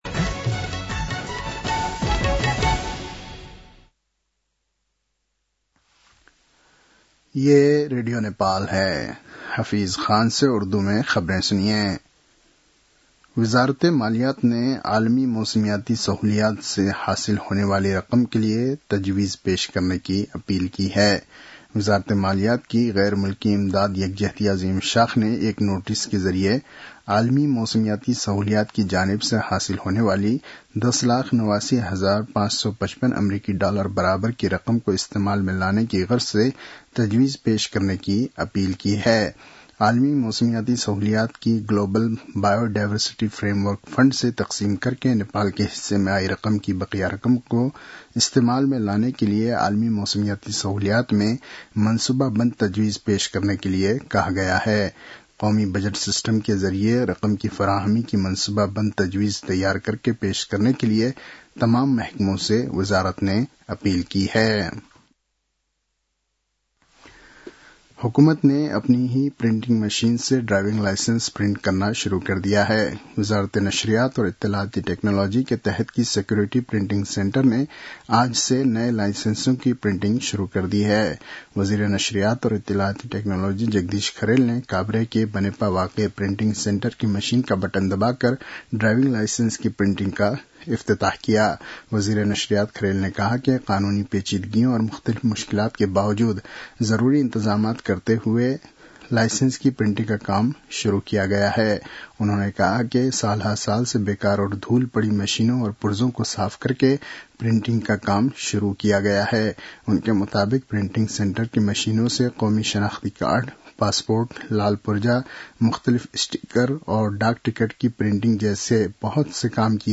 उर्दु भाषामा समाचार : २१ कार्तिक , २०८२
Urdu-NEWS-07-21.mp3